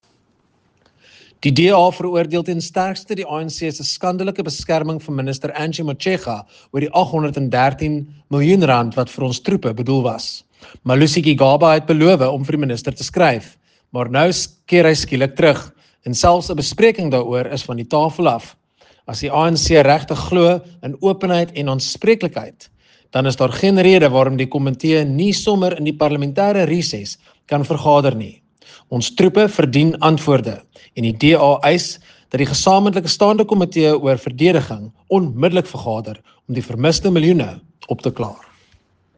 Afrikaans soundbite by Nicholas Gotsell MP.